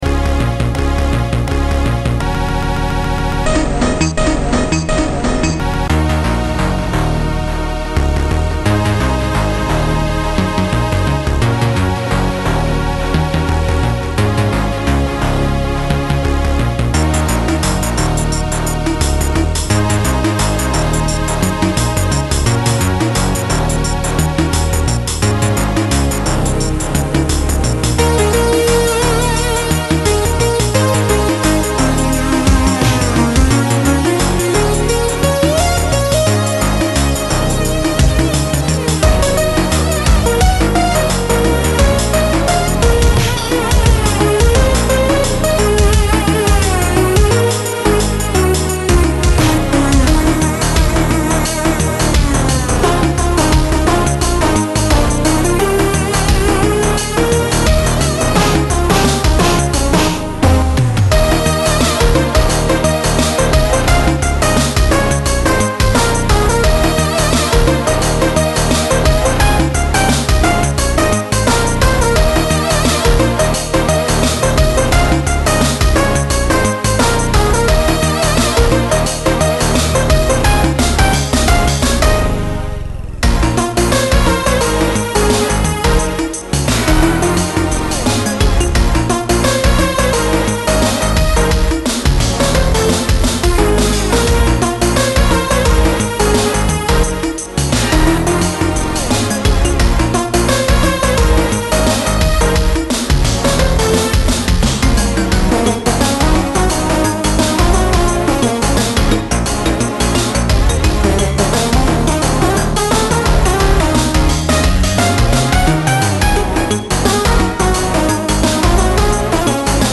Index of /Music/recovered/vaporwave/